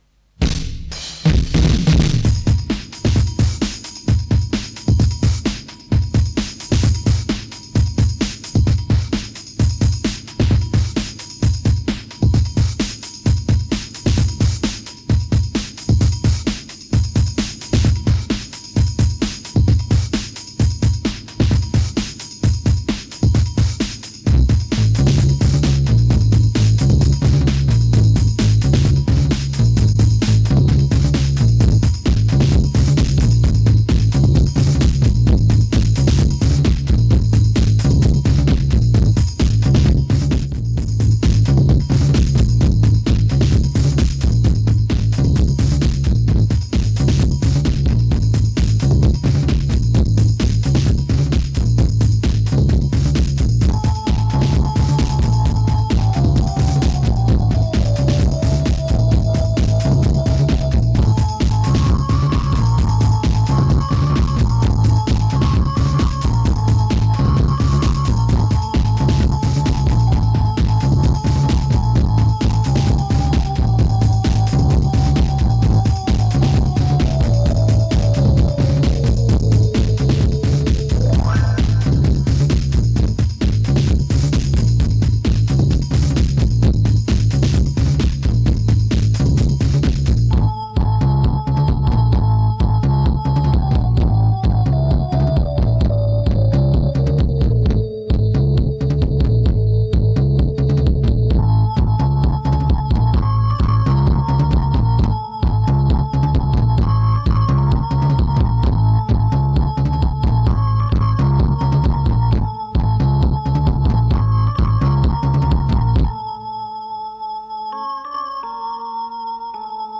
В смысле гитары это получено посредством дисторшна "Лель", допотопной советской кваки, и пульта со встроенным ревером-троилкой а-ля Брайан Мэй, плюс там эквалайзер был выставлен как-то узкими полосами с заваленным верхом, или что.
Записано вживую за один проход 2-мя человеками. Там нет наложений ещё, отсутствовала такая техника на руках.
Minaret_Electric_Drum_n_Base_pt2.mp3